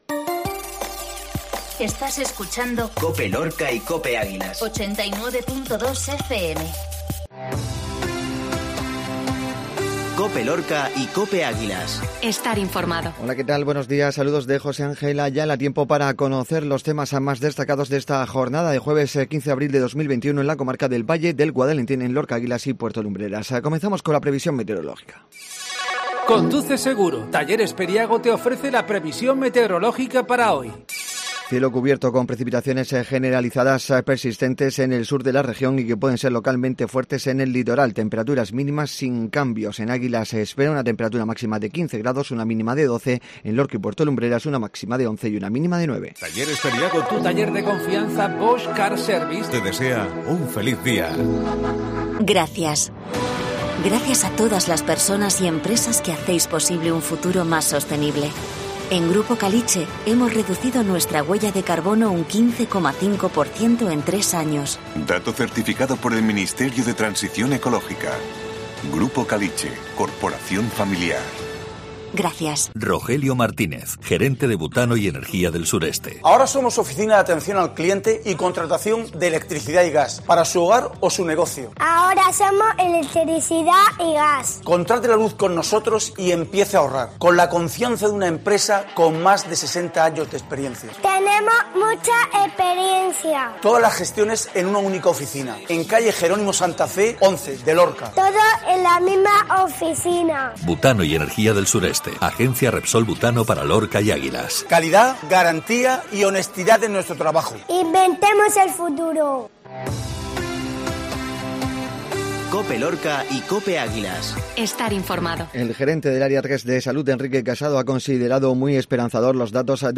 INFORMATIVO MATINAL JUEVES